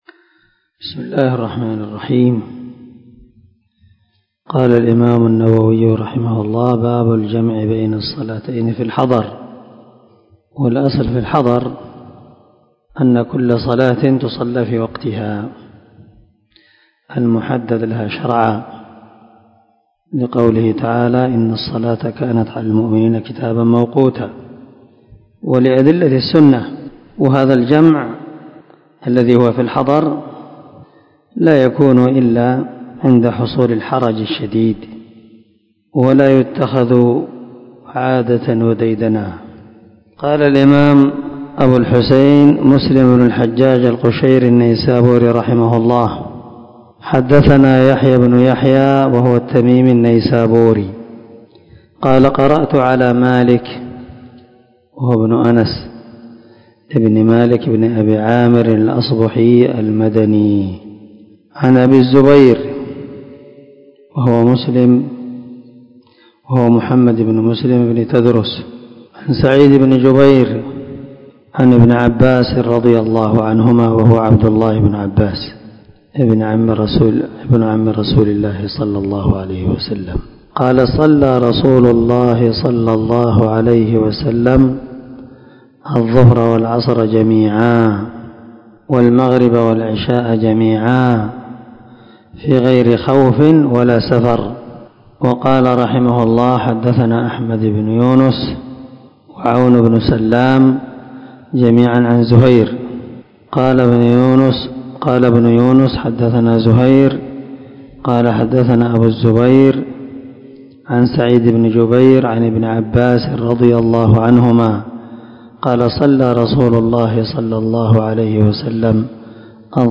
440الدرس 8 من شرح كتاب صلاة المسافر وقصرها حديث رقم ( 705 – 706 ) من صحيح مسلم
دار الحديث- المَحاوِلة- الصبيحة.